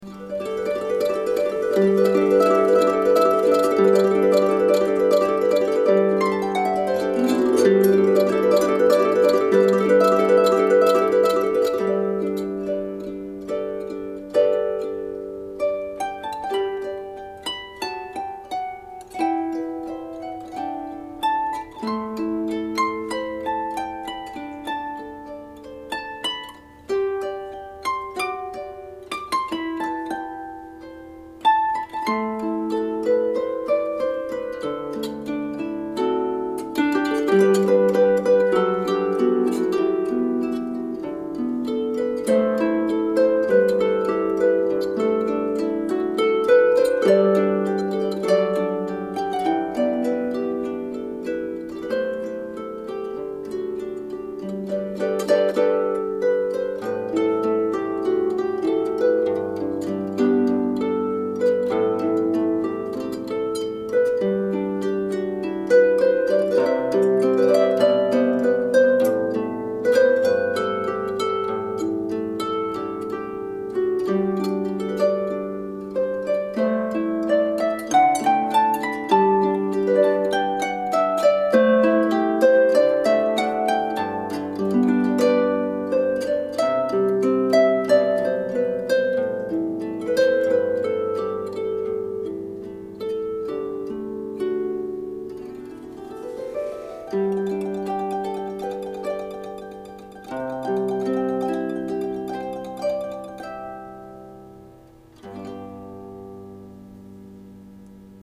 Smart Harp  29弦　スマートハープキット
しかも、低音から高音まで、十分な音量
本格派の音域　２９弦　Ｌｏｗ G2〜g6 ( ４オクターブ)
右の、プロハーピストのサウンドをお聞きください。